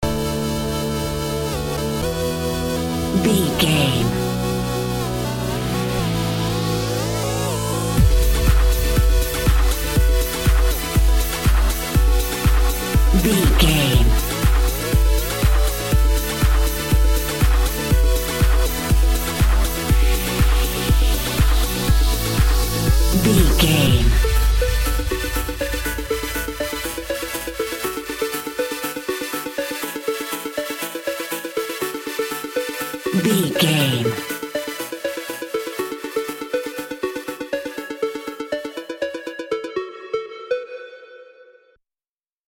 Aeolian/Minor
F#
groovy
uplifting
driving
energetic
repetitive
synthesiser
drum machine
house
electro dance
techno
trance
instrumentals
synth leads
synth bass
upbeat
uptempo